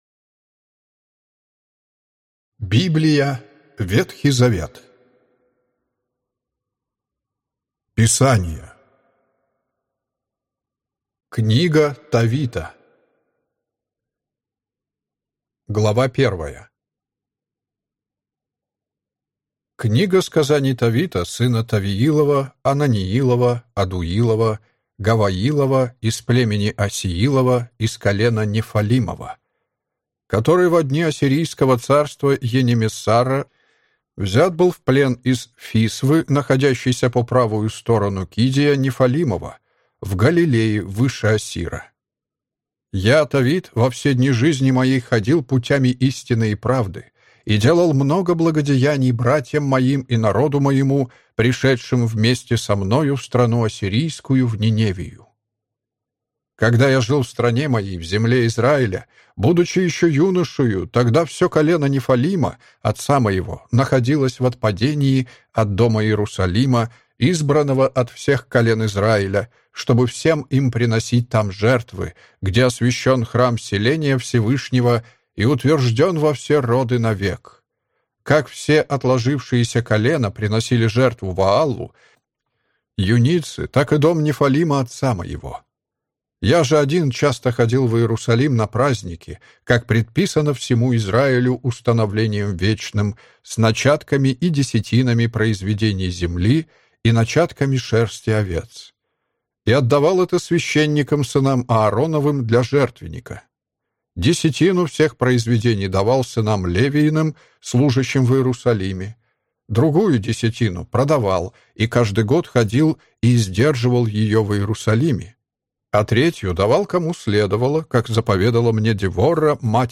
Аудиокнига Библия. Ветхий Завет (Писания) | Библиотека аудиокниг